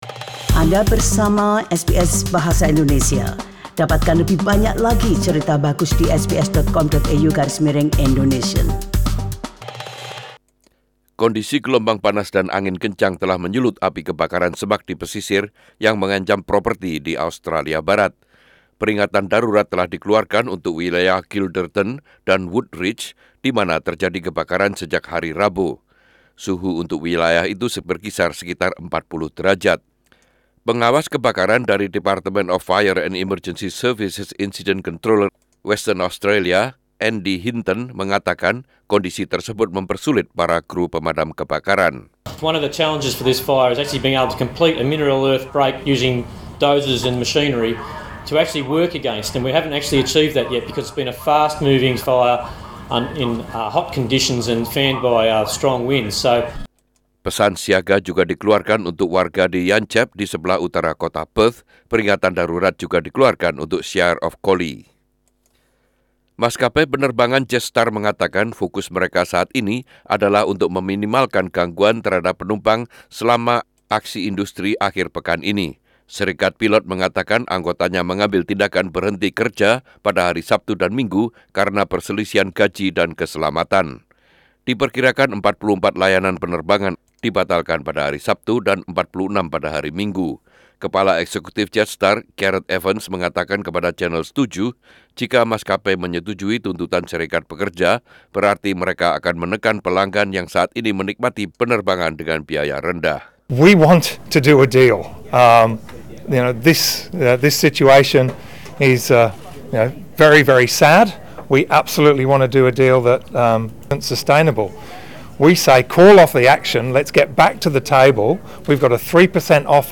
Warta Berita Radio SBS dalam Bahasa Indonesia - 15/12/2019